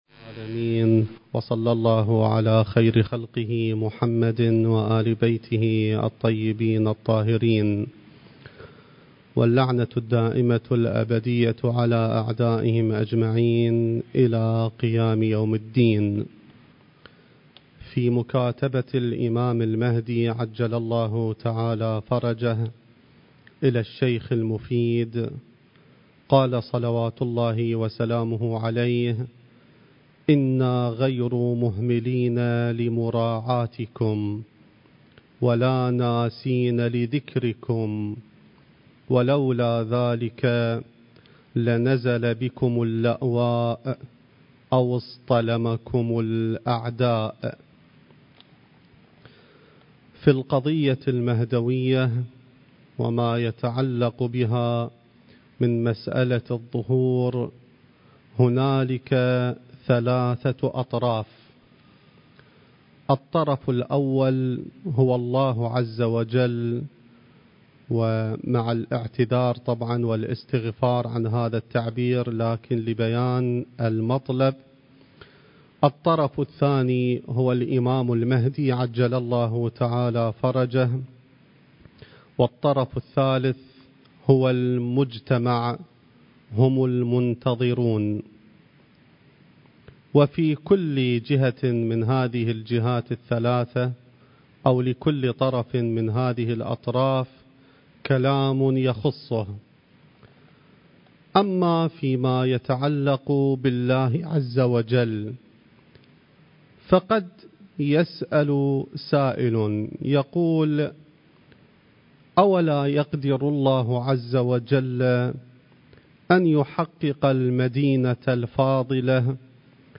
المكان: العتبة العلوية المقدسة الزمان: ذكرى ولادة الإمام المهدي (عجّل الله فرجه) التاريخ: 2020